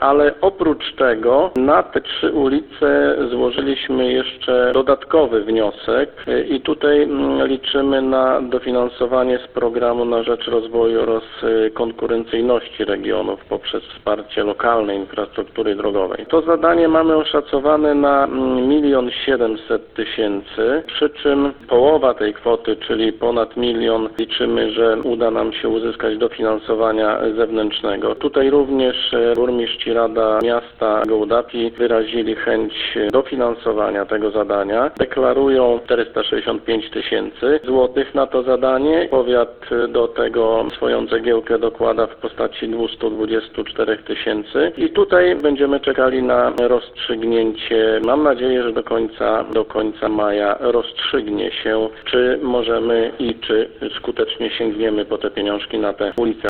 Decyzja o przyznaniu dofinansowania powinna zapaść jeszcze w kwietniu. Jak mówi starosta, z rezerwy budżetu państwa samorząd chce pozyskać środki również na remont ulic Cichej, Zielonej i Małej.